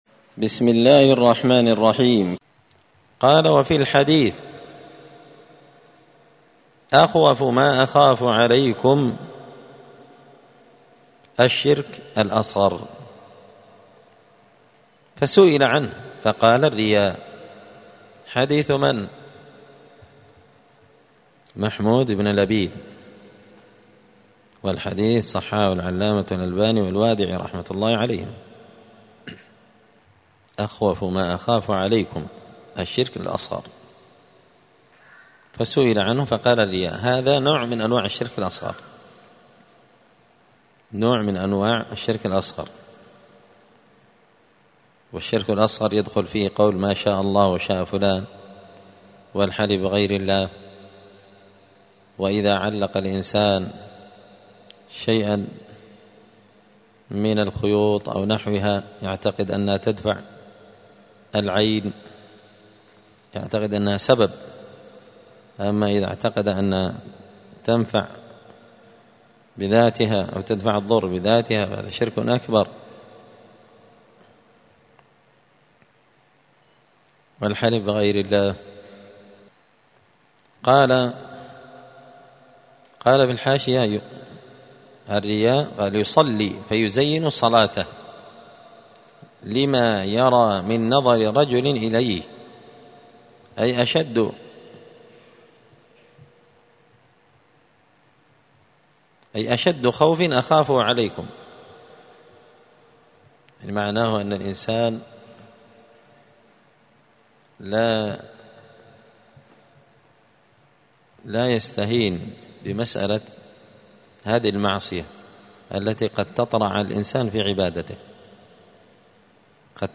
دار الحديث السلفية بمسجد الفرقان بقشن المهرة اليمن
*الدرس الثامن عشر (18) {تابع للباب الرابع باب الخوف من الشرك…}*